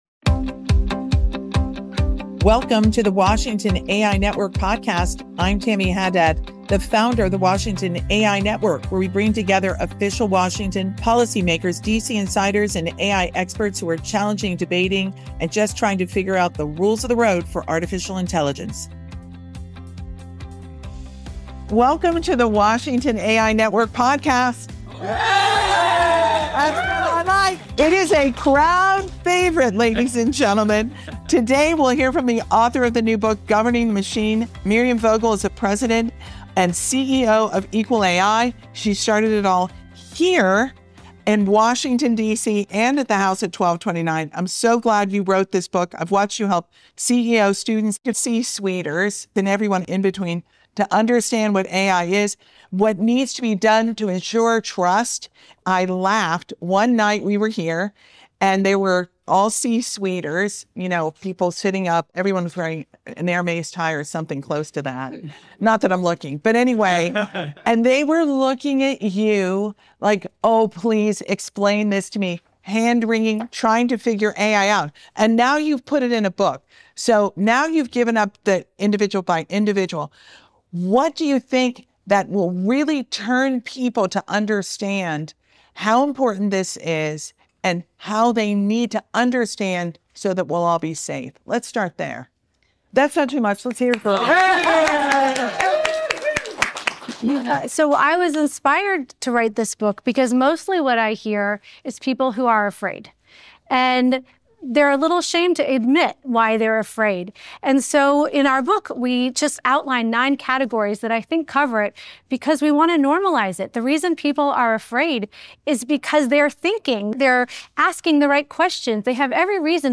conversation on AI trust, governance, and global competition